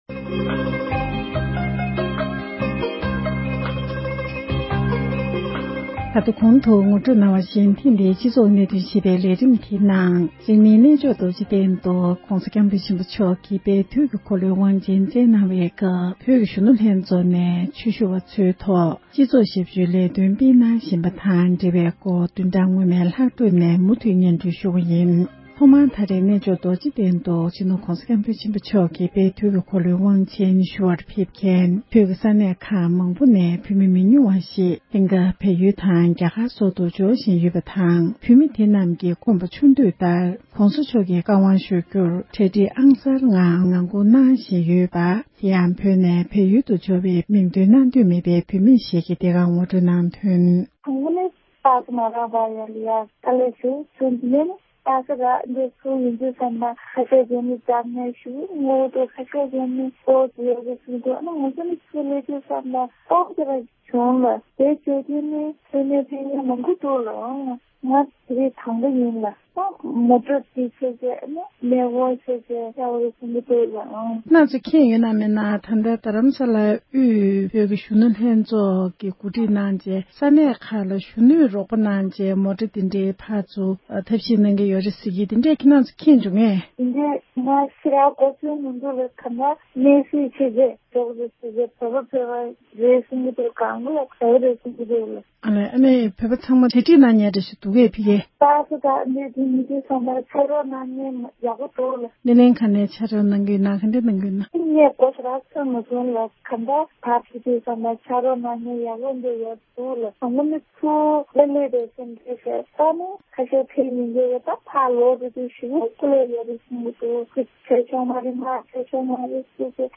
འབྲེལ་ཡོད་མི་སྣར་གནས་འདྲི་ཞུས་ཏེ་གནས་ཚུལ་ཕྱོགས་སྒྲིགས་ཞུས་པ་ཞིག་གསན་རོགས་ཞུ༎